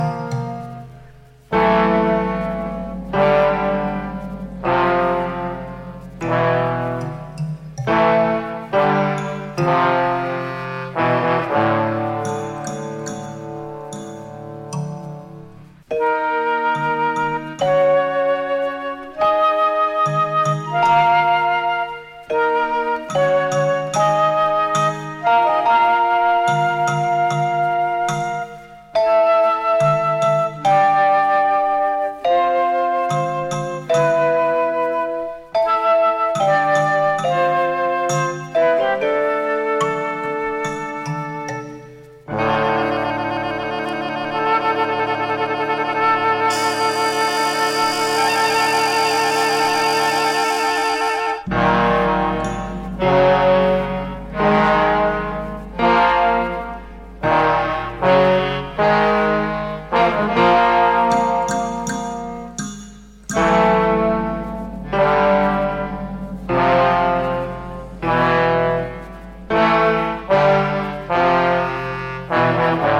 library music collection